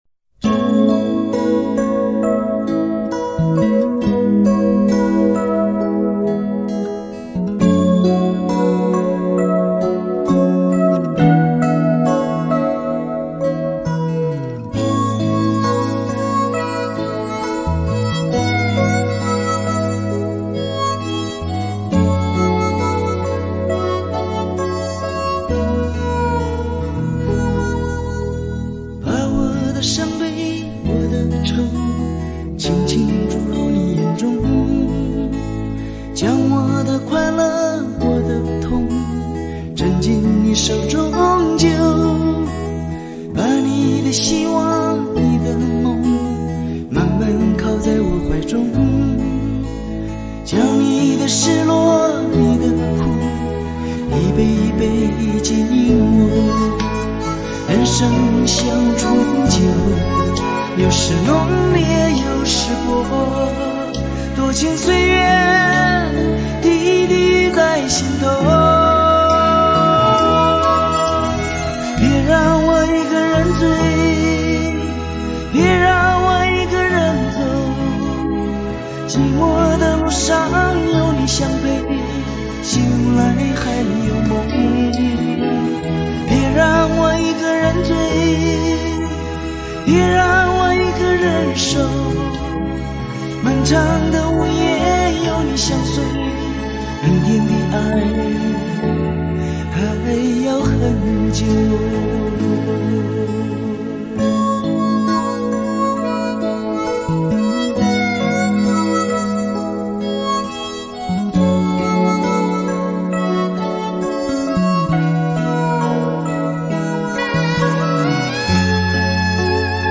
华语
经典歌曲